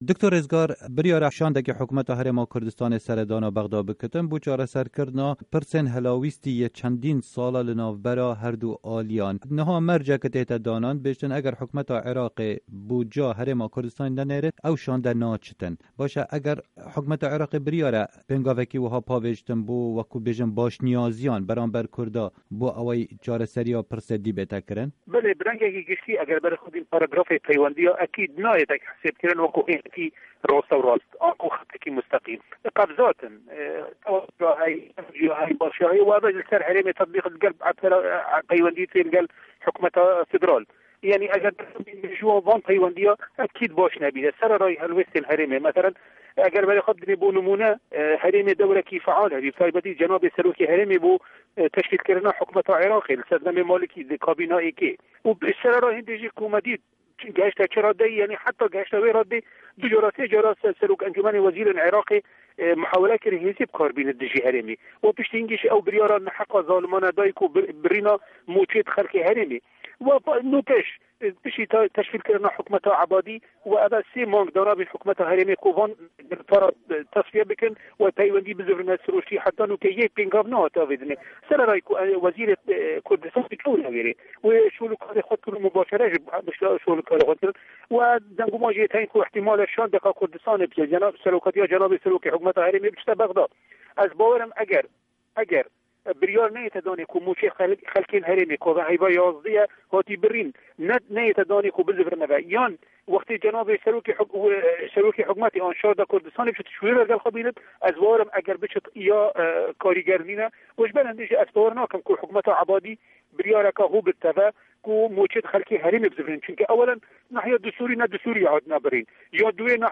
Di hevpeyvînekê de ligel Dengê Amerîka, Dr. Rizgar Qasim Mihemed, endamê berê yê parlemana Îraqê dibêje, destûra Îraqê mafên Kurdan parastiye.
Hevpeyvîn bi Dr. Rizgar Qasim re